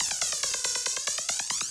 K-1 Perc 2.wav